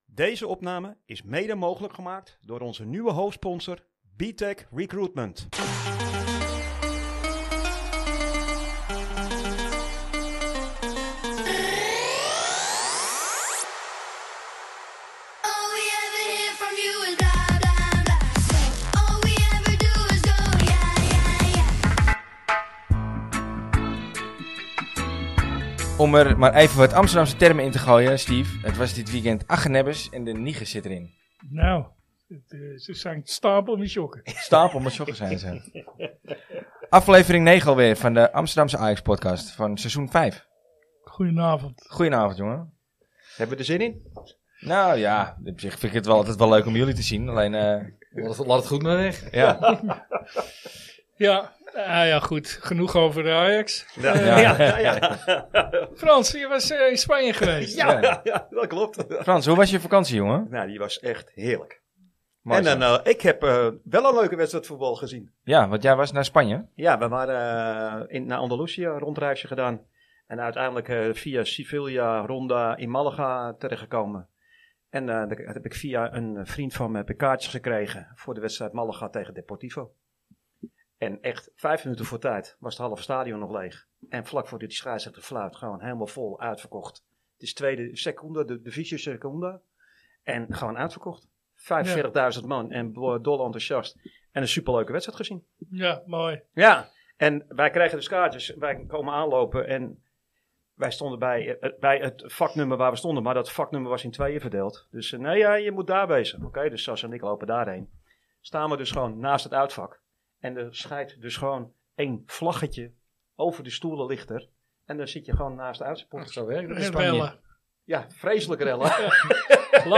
De Ajax podcast voor supporters, door supporters en met supporters! Amsterdamse jongens die de wedstrijd analyseren op een kritische manier, maar wel met de nodige humor, regelmatig interessante gasten vanuit de Ajax supporterswereld of vanuit de voetbalwereld zelf.